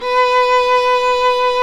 Index of /90_sSampleCDs/Roland - String Master Series/STR_Viola Solo/STR_Vla1 % + dyn